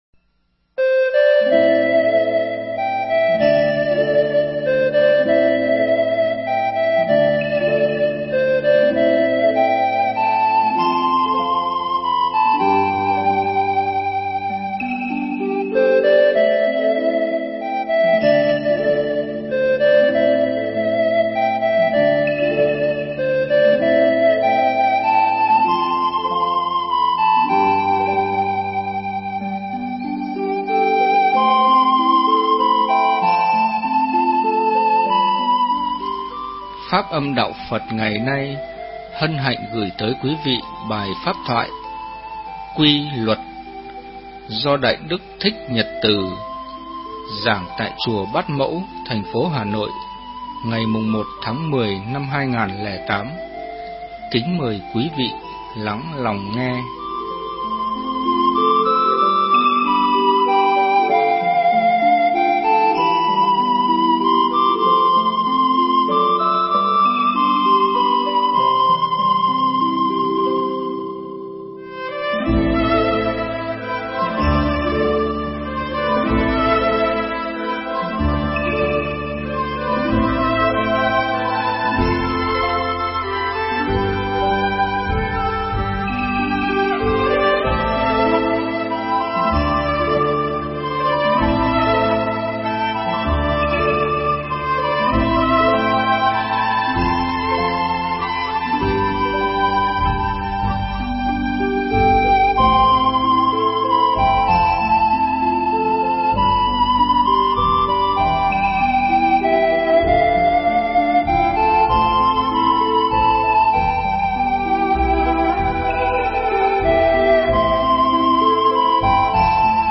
Mp3 Thuyết Giảng Quy luật – Thượng Tọa Thích Nhật Từ Giảng tại Chùa Bát Mẫu – Hà Nội, ngày 1 tháng 10 năm 2008